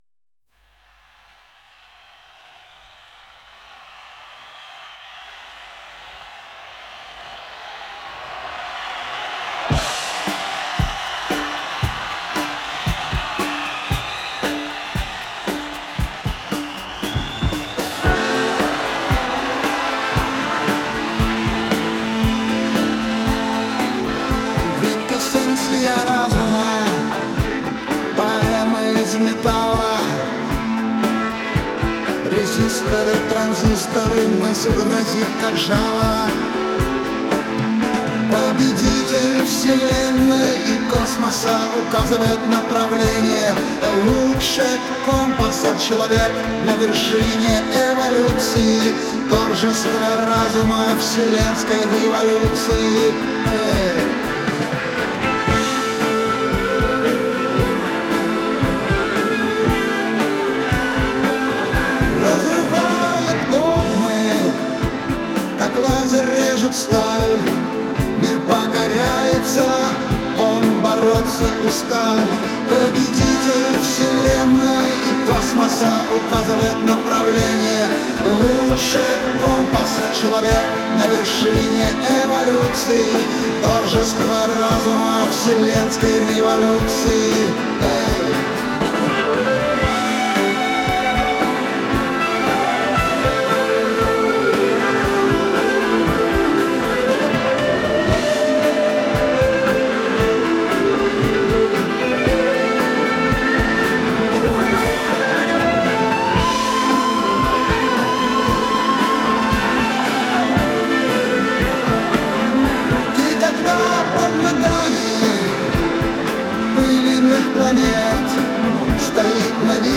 Неожиданно, к концу песни ИИ стал аномально проявлять себя, появились странные, нечеловеческие звуки, а потом раздалась речь ИИ. Это были странные слова, плохо понятные, но в данной речи было отчетливо слово “слуги” и было ясно, что речь велась относительно человечества.